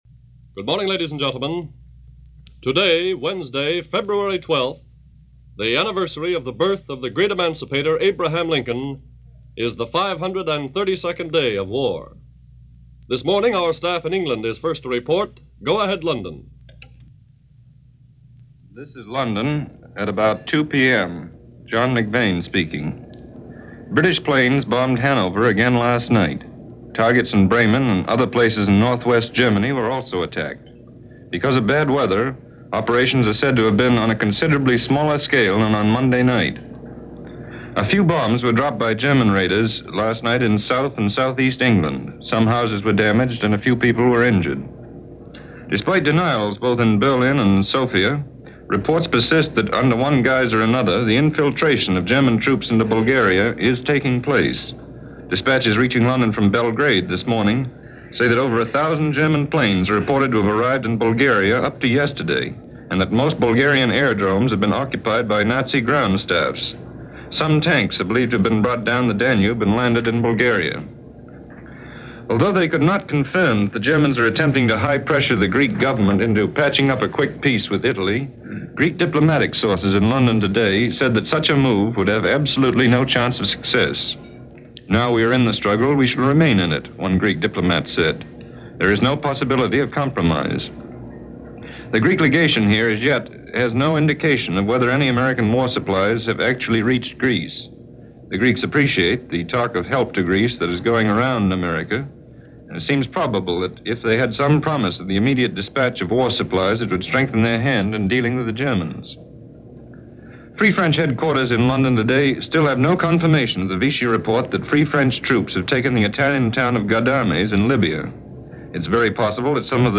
News for February 12, 1941 – NBC Radio